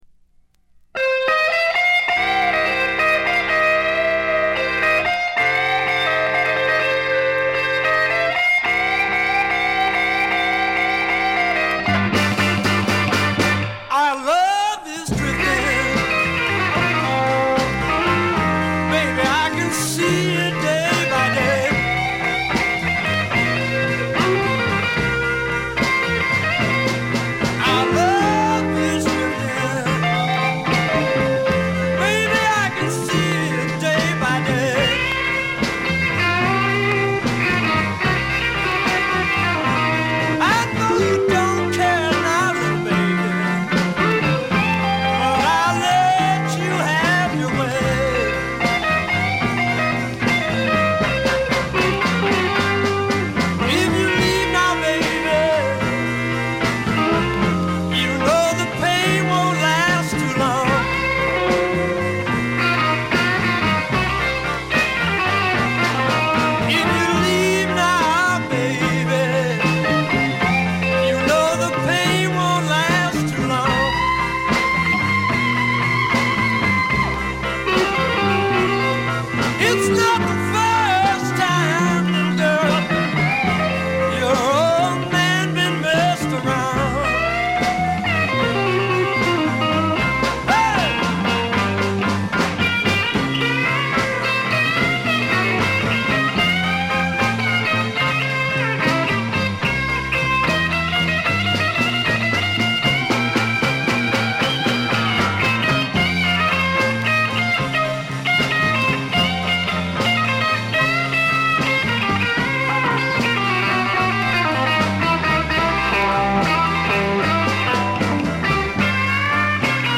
部分試聴ですが、わずかなノイズ感のみ。
暴風雨のような凄まじい演奏に圧倒されます。
試聴曲は現品からの取り込み音源です。
Harmonica